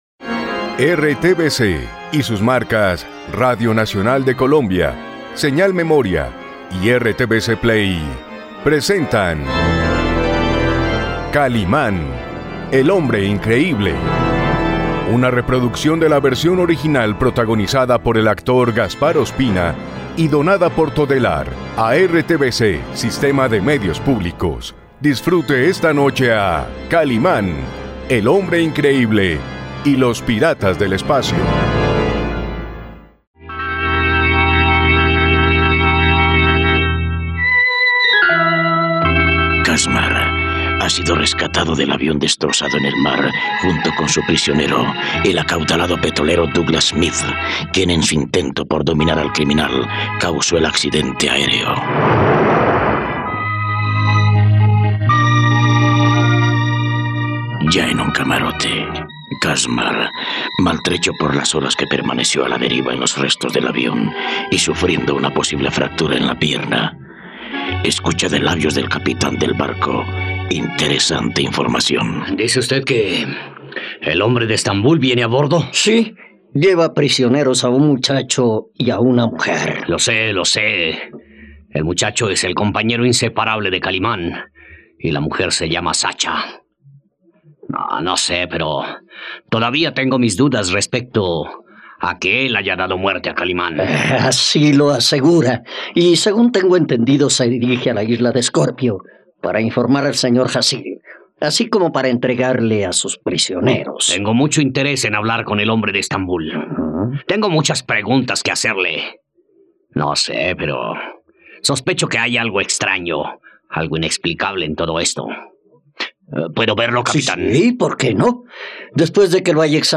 ..No te pierdas la radionovela completa de Kalimán y los piratas del espacio.